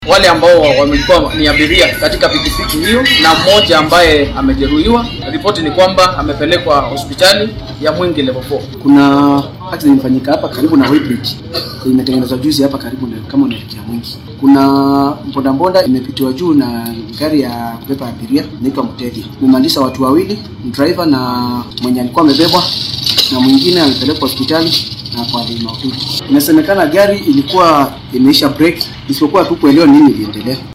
Goobjoogayaasha shilkaasi ayaa warbaahinta uga warramay sida ay wax u dhaceen.
Goobjoogayaasha-shilka-Mwingi.mp3